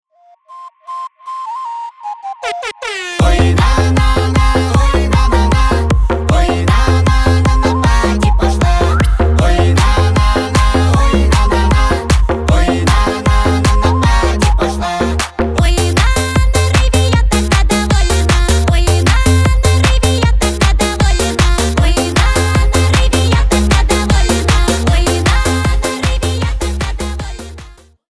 • Качество: 320 kbps, Stereo
Поп Музыка
весёлые